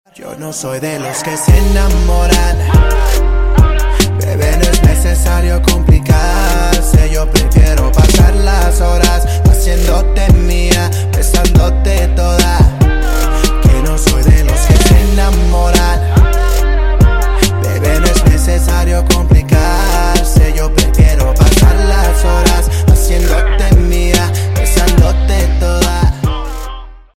• Качество: 320, Stereo
поп
Хип-хоп
латиноамериканские
Latin Pop